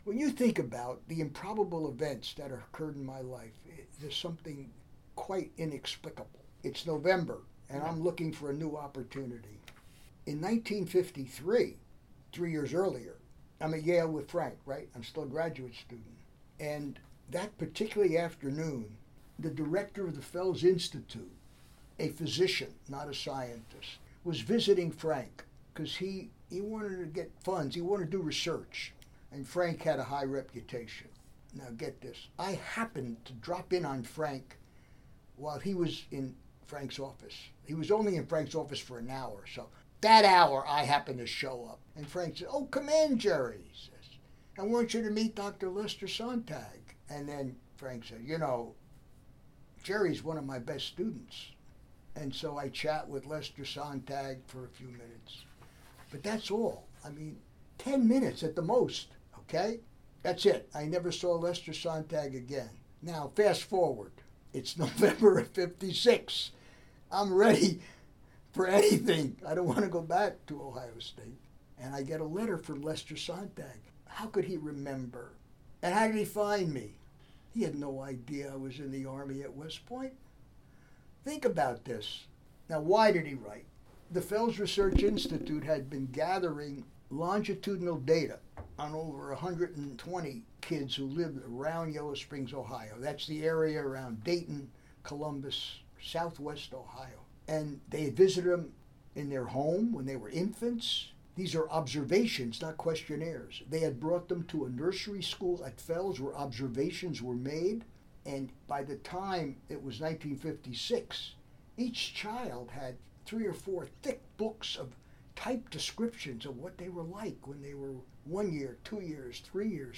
Here is Dr. Kagan describing how he came to this project at the Fels Institute at Antioch College, and its impact.
The chutzpahchutzpah of Dr. Kagan comes through so clearly in  this audio and I hope you enjoy it.